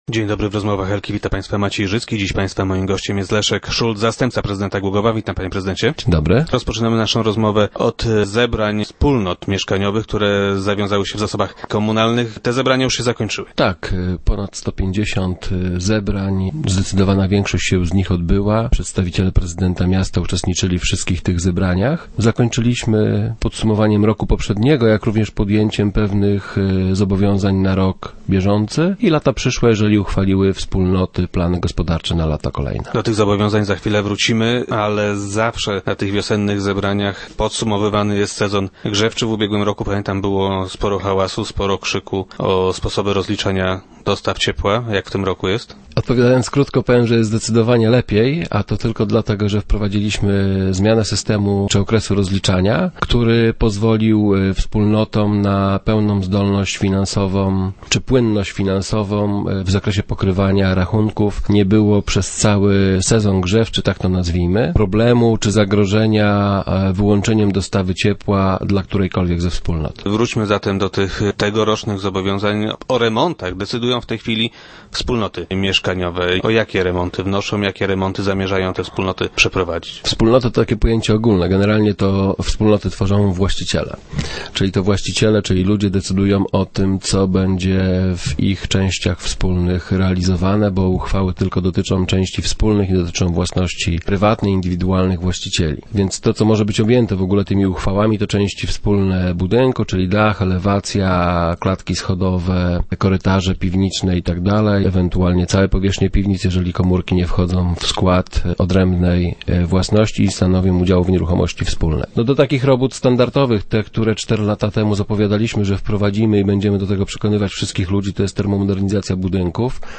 Dotyczy on jednak nie tylko Głogowa - twierdzi wiceprezydent Leszek Szulc, który był dziś gościem Rozmów Elki.